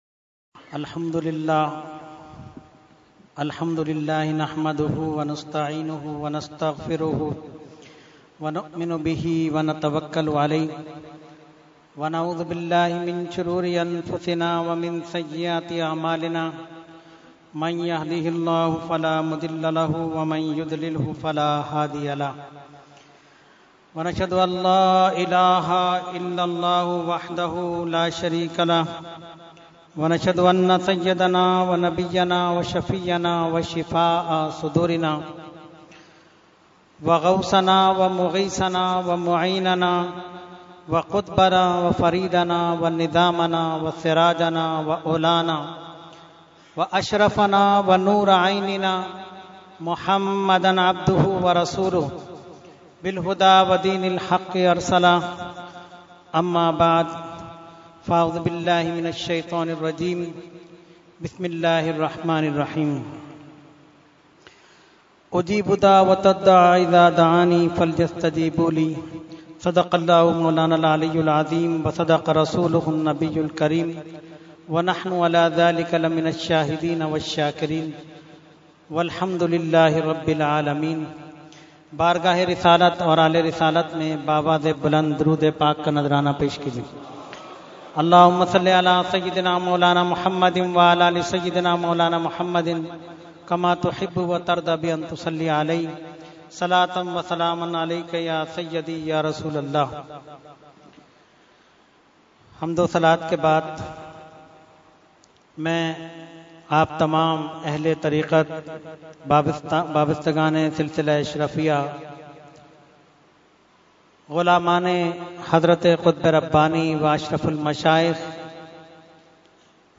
Category : Speech | Language : UrduEvent : Khatam Hizbul Bahr 2016